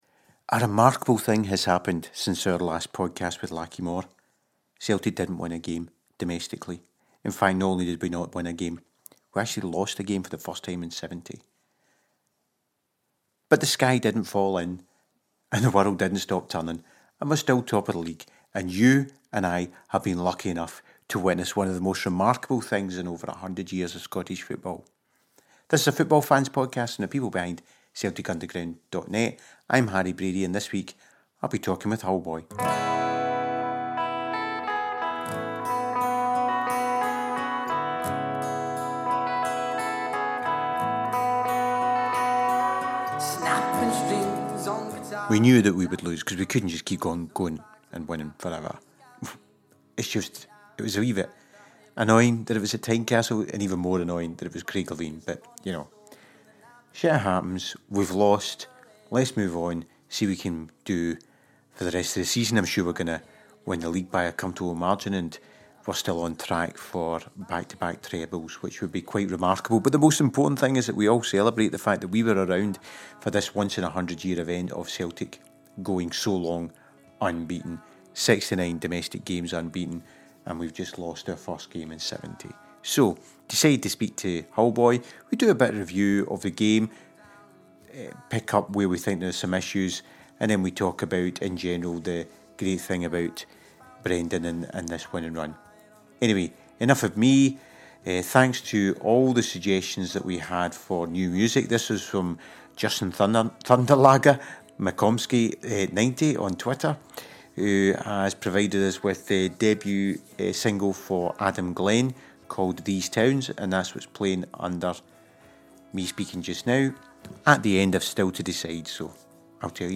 After the game against Hearts and the end of the record breaking run I thought I should quickly pull together a podcast and discuss the game, the fall-out, the magnificant run and our hopes for the furture. It’s all here along with the music.